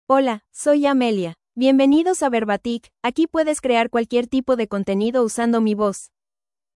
Amelia — Female Spanish (United States) AI Voice | TTS, Voice Cloning & Video | Verbatik AI
Amelia is a female AI voice for Spanish (United States).
Voice sample
Amelia delivers clear pronunciation with authentic United States Spanish intonation, making your content sound professionally produced.